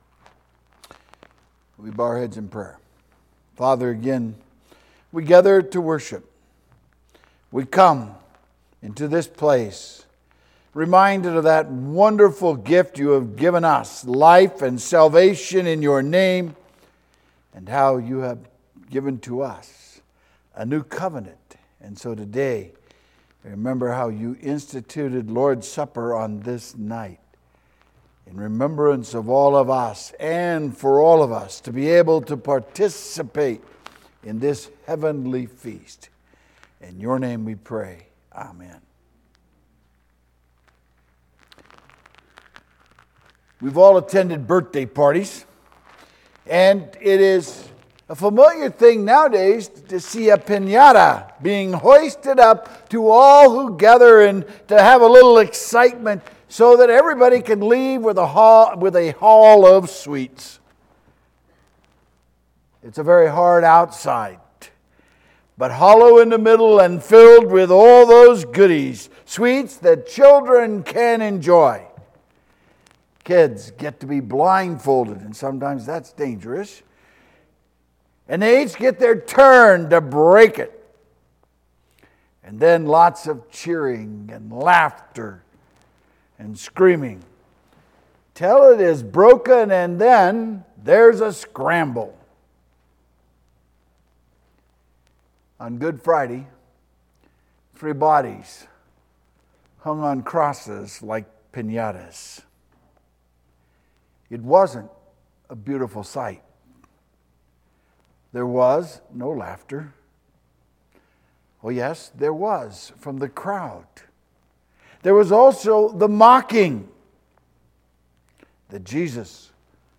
Sermon Maundy Thursday April 6, 2023